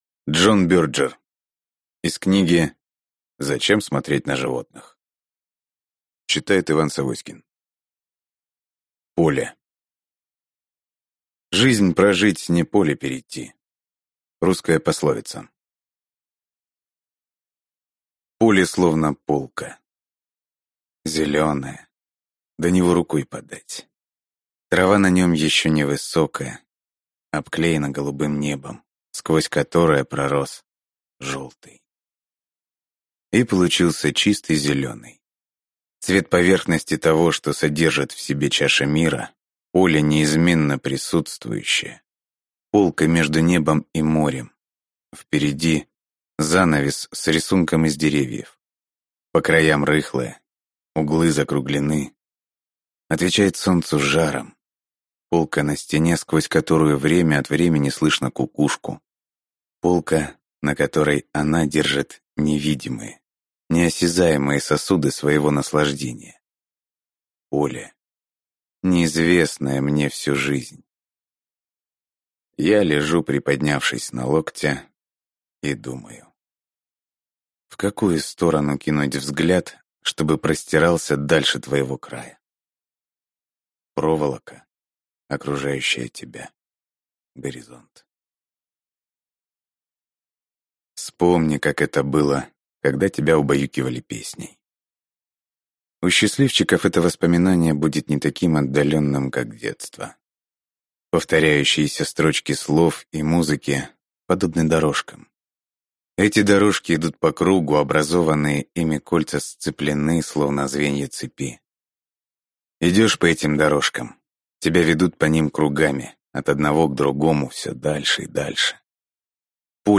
Аудиокнига Поле | Библиотека аудиокниг